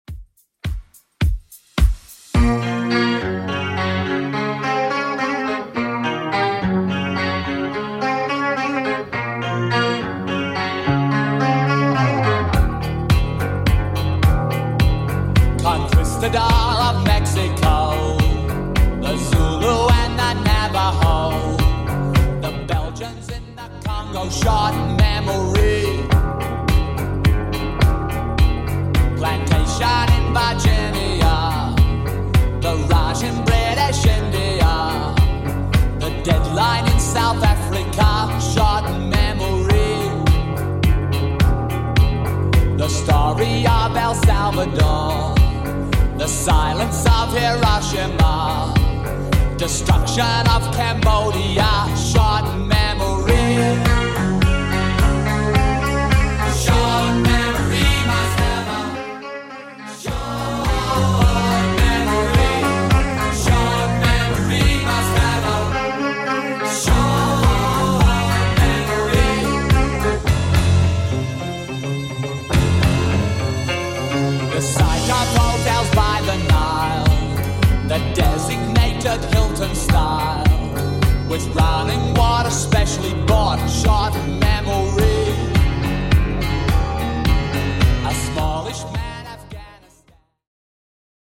BPM: 106 Time